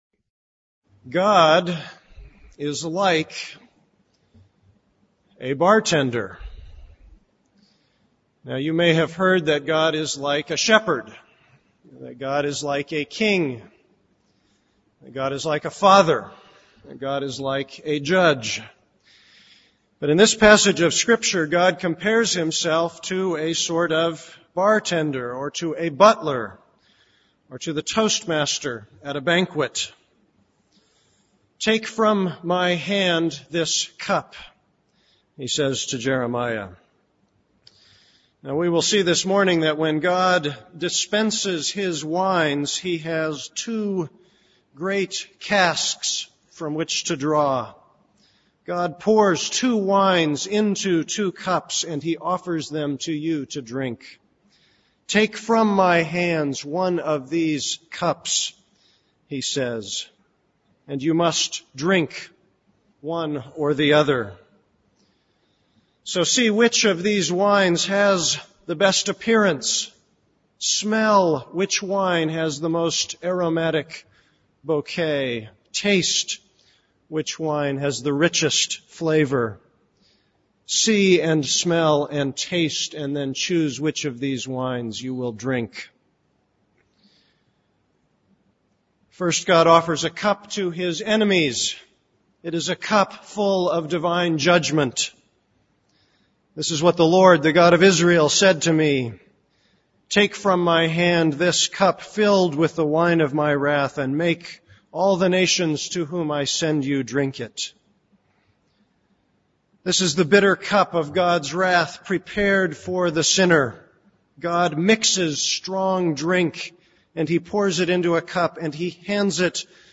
This is a sermon on Jeremiah 25:15-38.